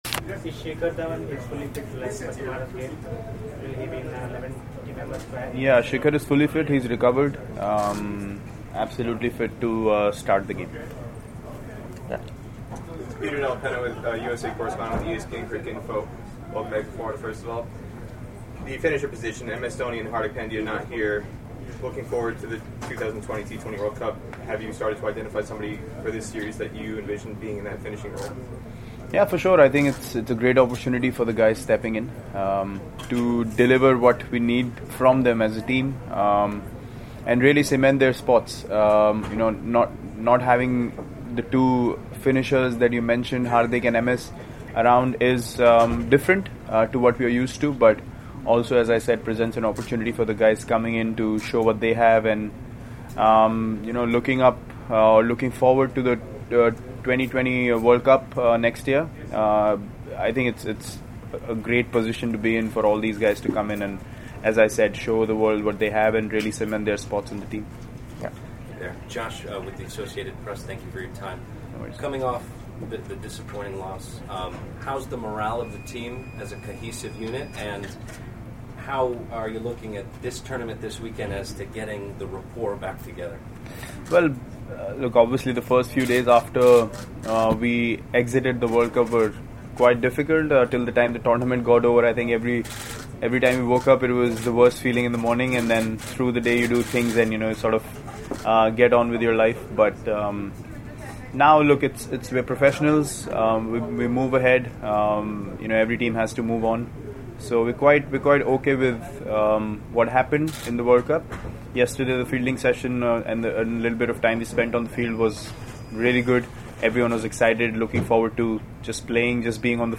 Virat Kohli, Captain, Indian Cricket Team. He spoke to the media in Florida on Friday on the eve of the team’s 1st T20I against West Indies.